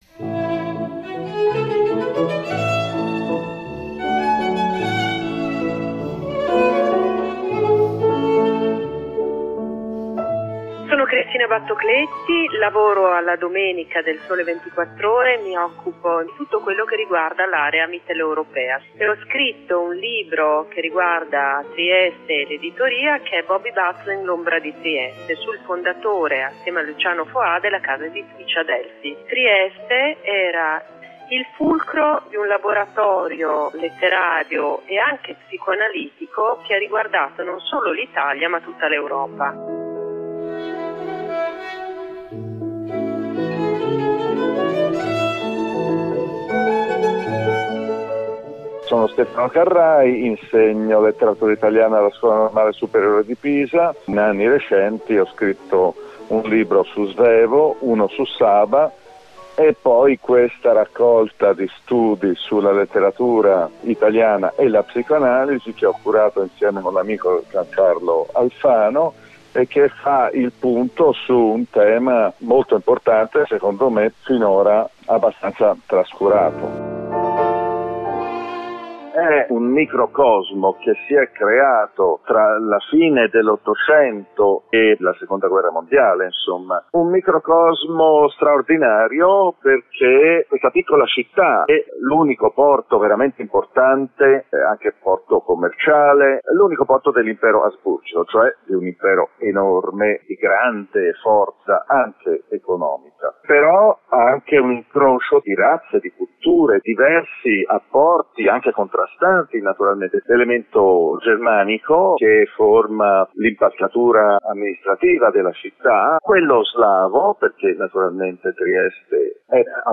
documentario